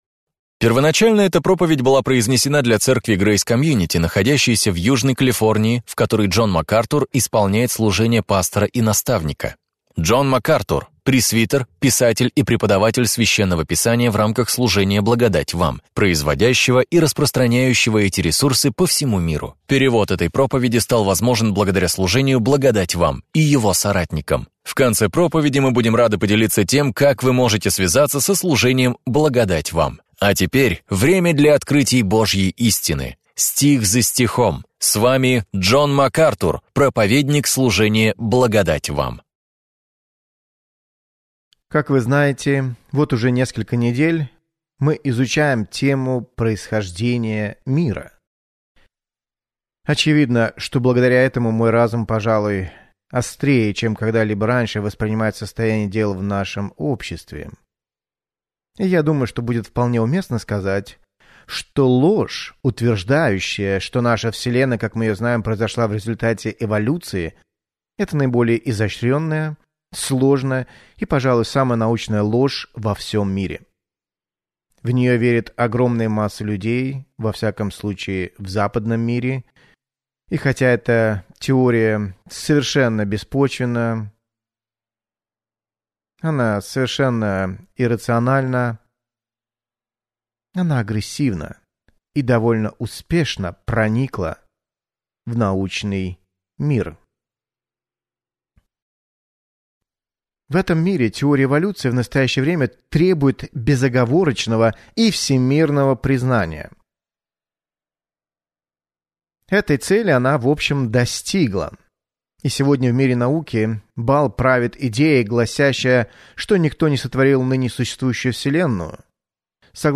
Какую роль должны играть Библия и наука в личных убеждениях людей касательно физической Вселенной? В своей проповеди «Битва за начало» Джон Макартур раскрывает суть этих споров